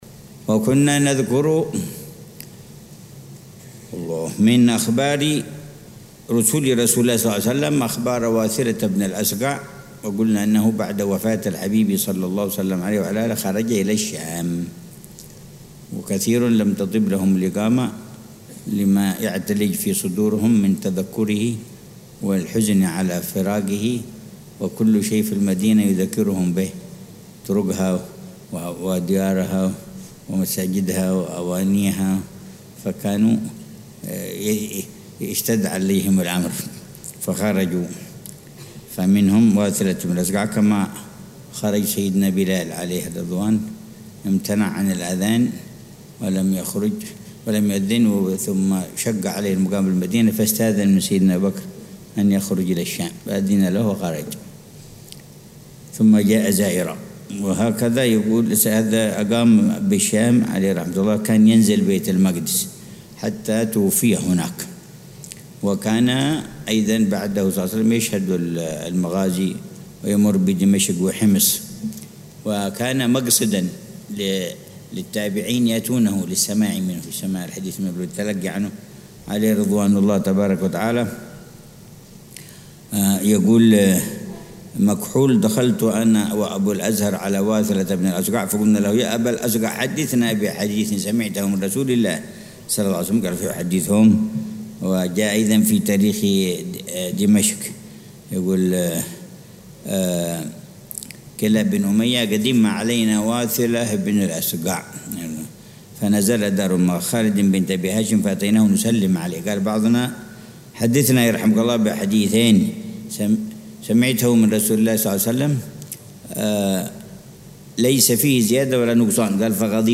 درس السيرة النبوية - رُسُل رسول الله ﷺ: واثلة بن الأسقع - 3
من دروس السيرة النبوية التي يلقيها العلامة الحبيب عمر بن محمد بن حفيظ، ضمن دروس الدورة التعليمية الحادية والثلاثين بدار المصطفى بتريم للدراسات